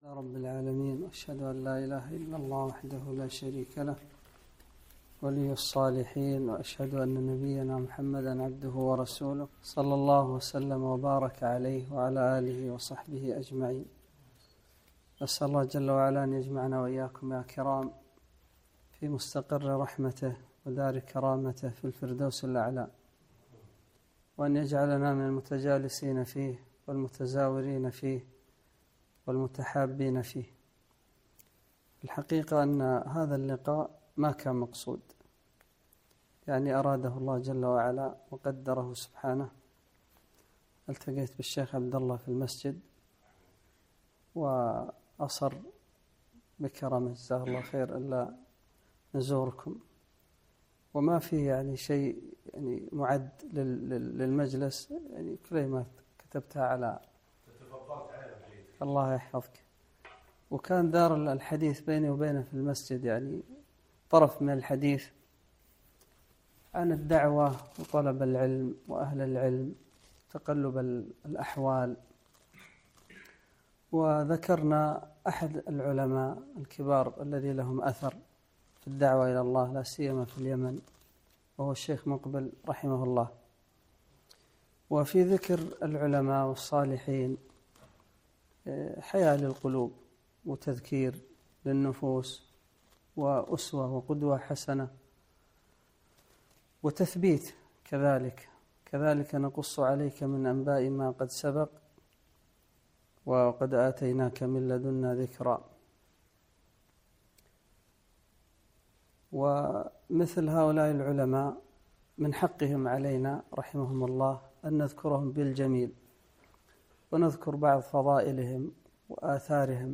محاضرة - حدثنا الوادعي